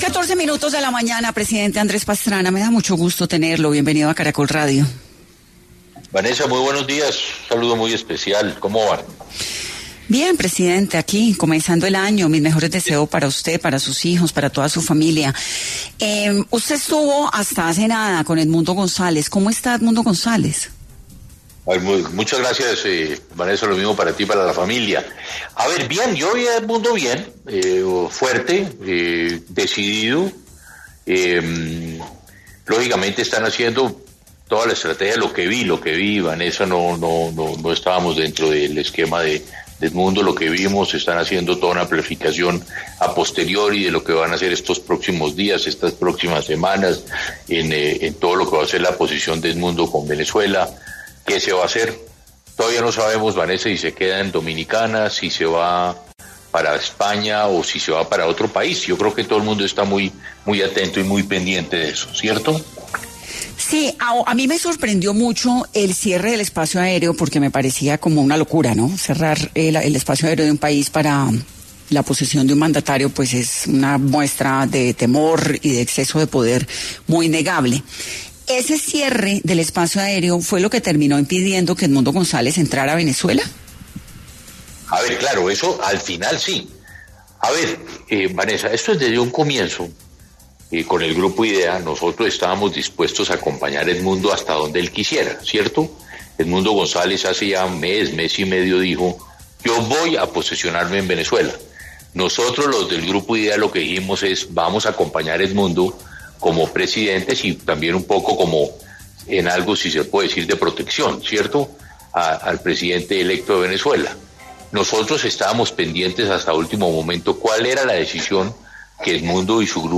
Andrés Pastrana, expresidente de Colombia, estuvo en Caracol Radio y habló sobre la posesión de Nicolás Maduro en Venezuela y la situación migratoria que enfrentará Colombia